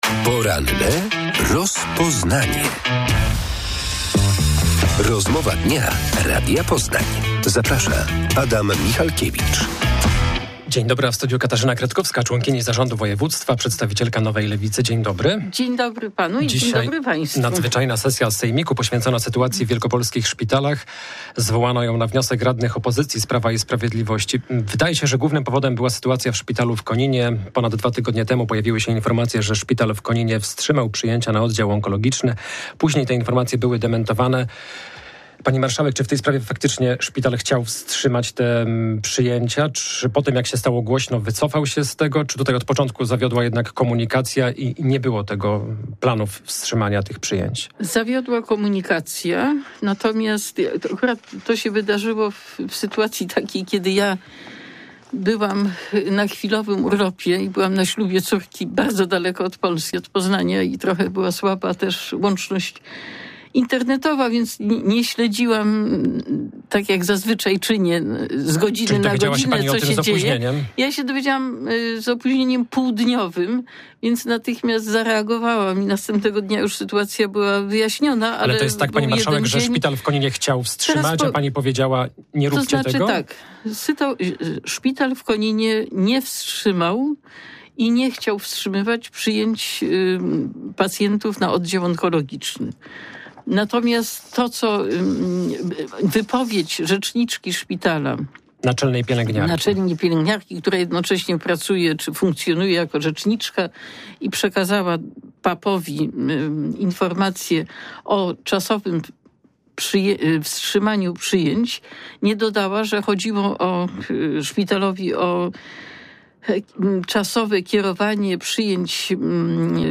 Radni sejmiku w południe spotkają się na nadzwyczajnej sesji poświęconej sytuacji w wielkopolskich szpitalach. W porannej rozmowie Radia Poznań członkini zarządu województwa Katarzyna Kretkowska z Nowej Lewicy przekonywała, że dzisiejsze posiedzenie jest niepotrzebne, bo w szpitalach nie dzieje się nic złego.